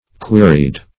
Queried - definition of Queried - synonyms, pronunciation, spelling from Free Dictionary
Query \Que"ry\, v. t. [imp. & p. p. Queried; p. pr. & vb. n.